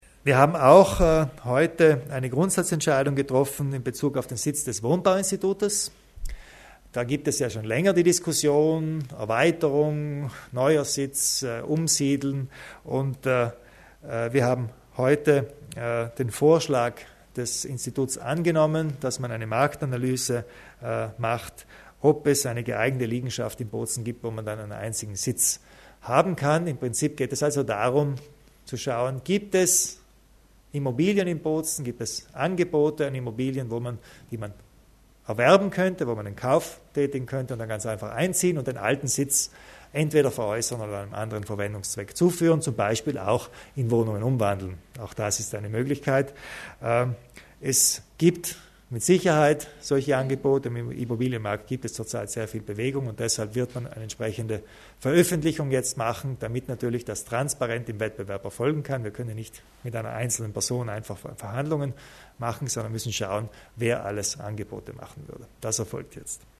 Landeshauptmann Kompatscher zum neuen Sitz des Wohnbauinstituts